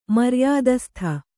♪ maryādastha